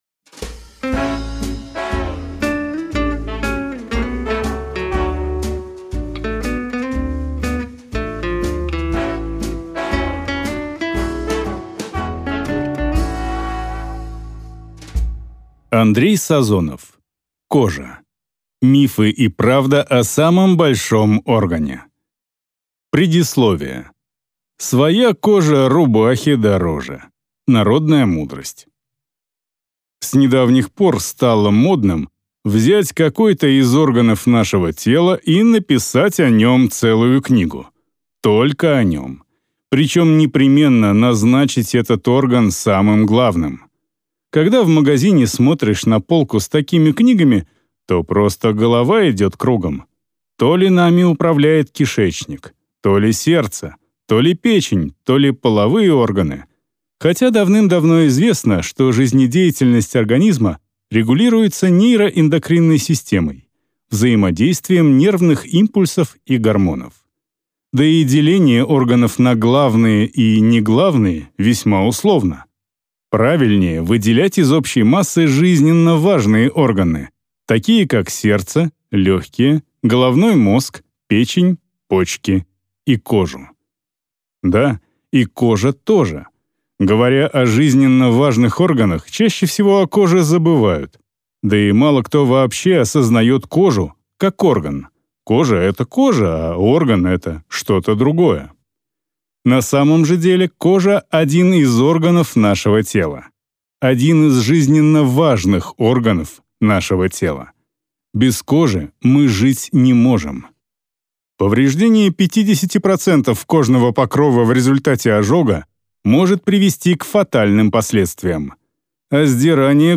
Аудиокнига Кожа: мифы и правда о самом большом органе | Библиотека аудиокниг